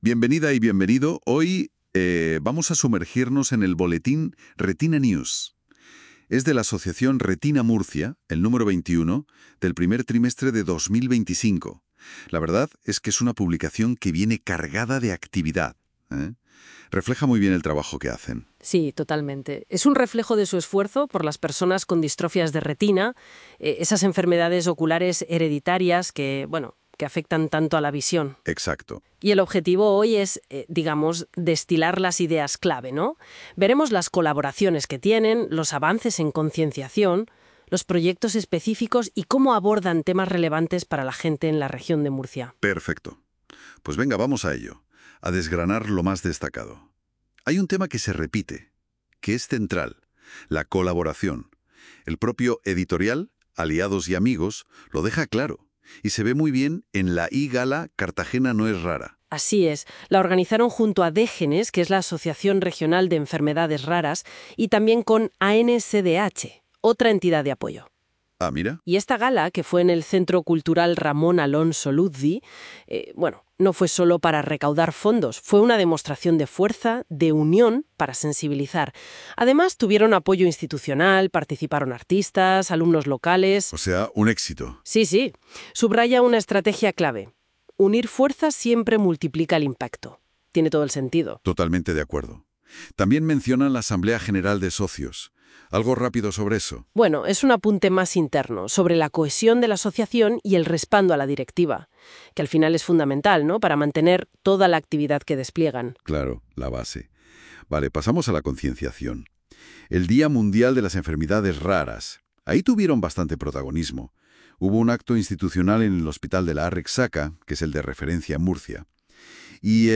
FORMATO AUDIO LIBRO